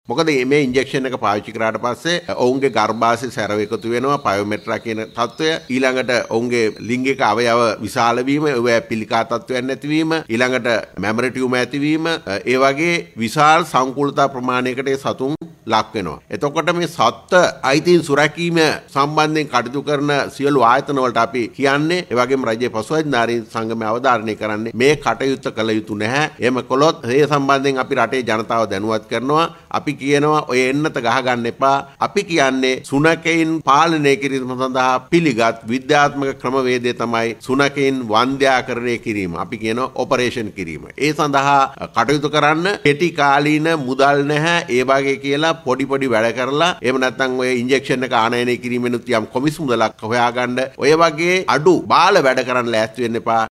ඔහු මේ බව පැවසුවේ කොළඹ පැවති මාධ්‍ය හමුවකදියි.